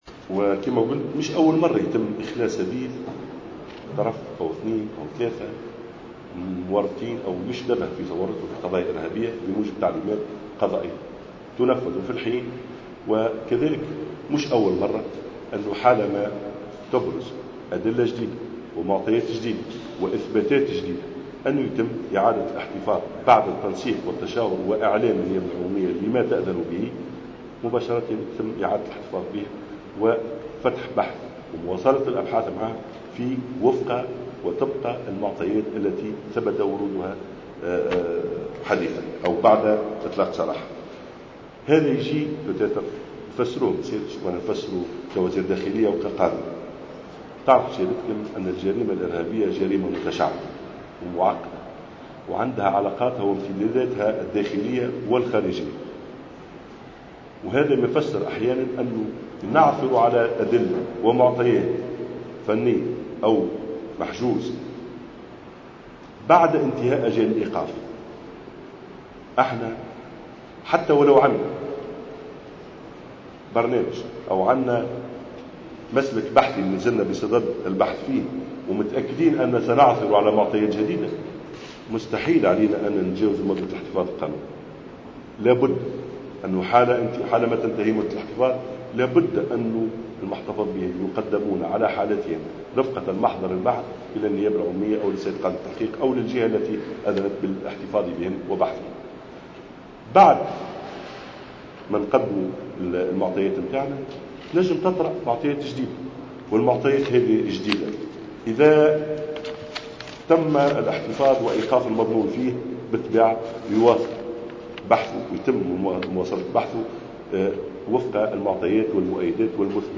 Najem Gharsalli a ajouté, lors d'une conférence de presse tenue ce mercredi à Tunis, que cette décision intervient suite à des informations selon lesquelles ils seraient impliqués dans d'autres affaires terroristes.